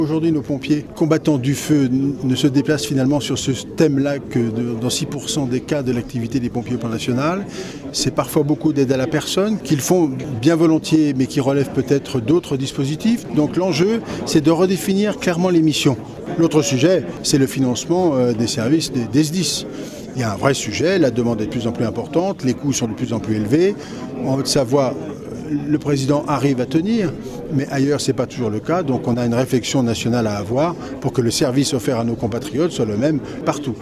Le ministre François-Noël Buffet :